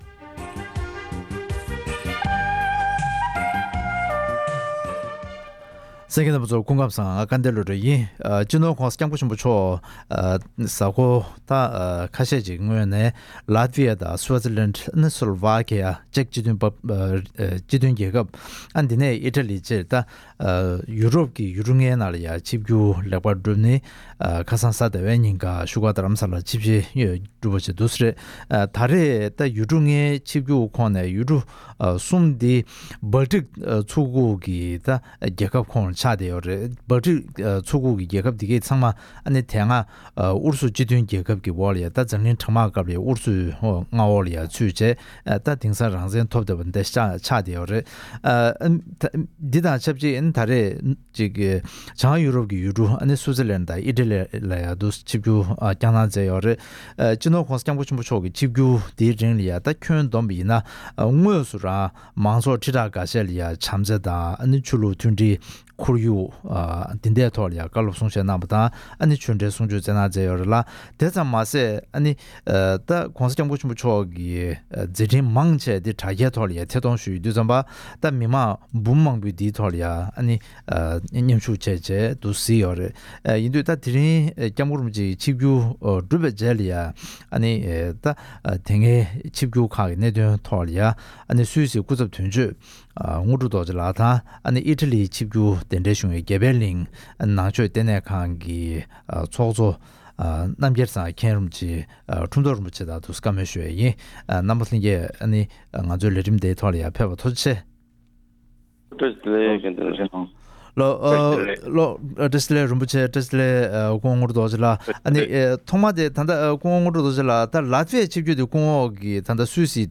སྤྱི་ནོར་༧གོང་ས་༧སྐྱབས་མགོན་ཆེན་པོ་མཆོག་གིས་ཡོ་རོབ་ཀྱི་ཡུལ་གྲུ་ལྔའི་ནང་གི་ཆིབས་བསྒྱུར་མཛད་འཆར་ལེགས་པར་གྲུབ་ཡོད་པའི་ཐད་གླེང་མོལ།